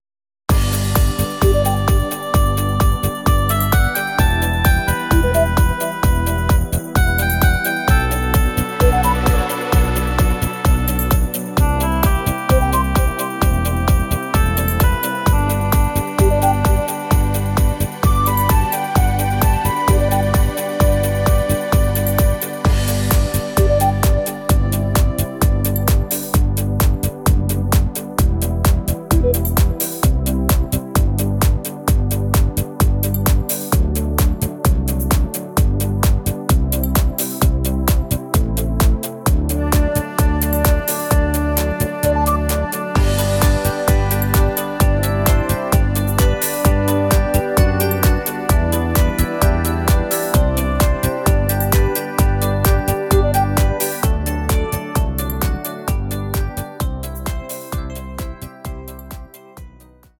Rhythmus  Discofox